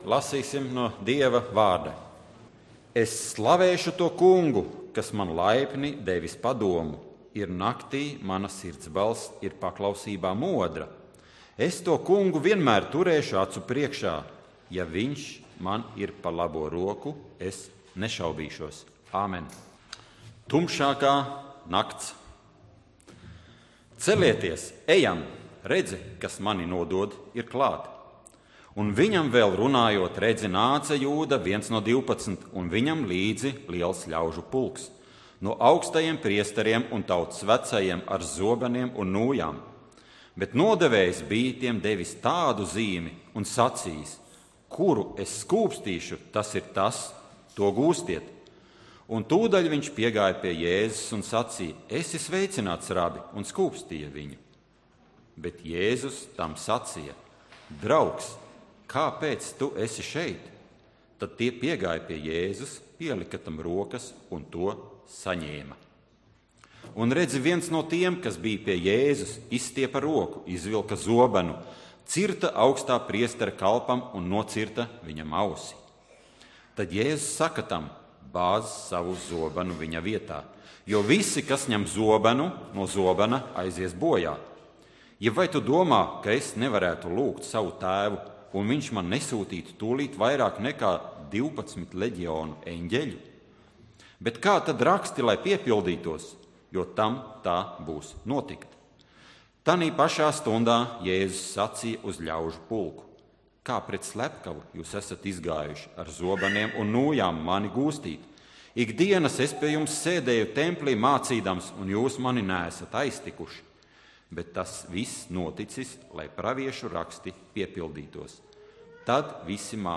Dievkalpojums 17.01.2015: Klausīties
Svētrunas